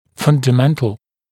[ˌfʌndə’mentl][ˌфандэ’мэнтл]фундаментальный, основной, основополагающий